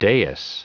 Prononciation du mot : dais